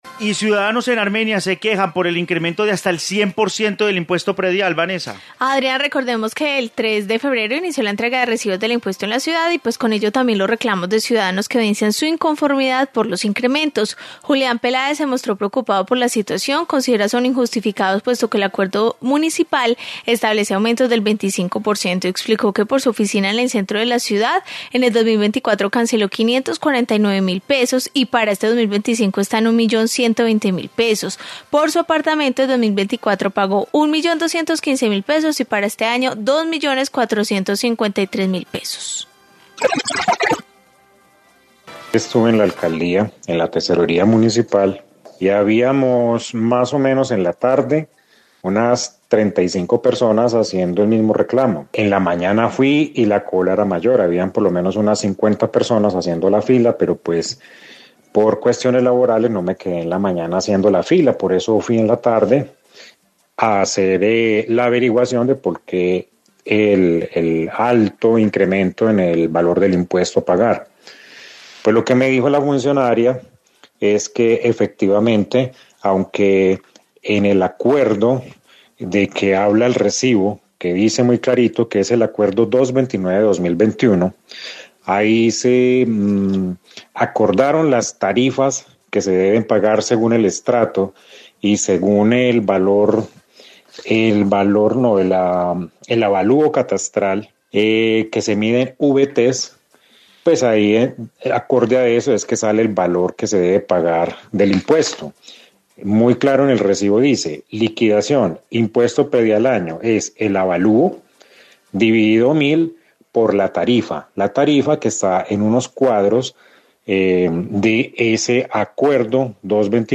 Informe queja impuesto predial